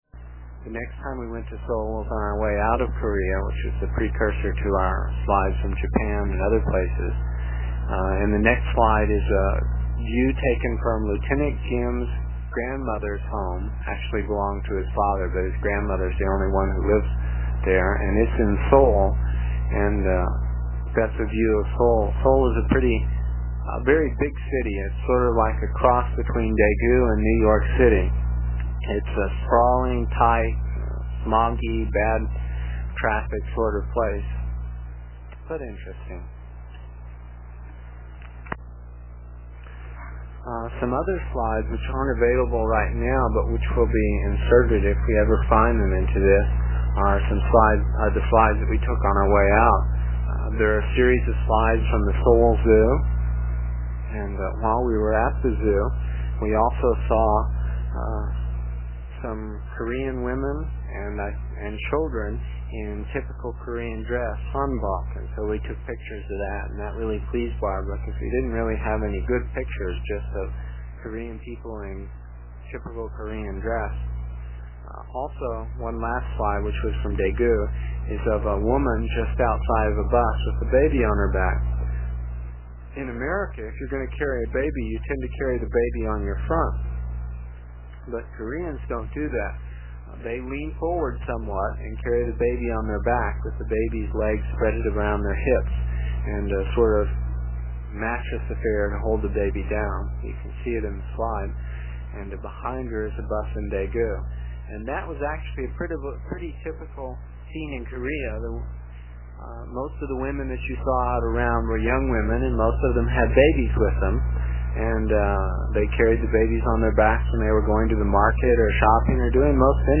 It is from the cassette tapes we made almost thirty years ago. I was pretty long winded (no rehearsals or editting and tapes were cheap) and the section for this page is about two minutes and will take about 40 seconds to download with a dial up connection.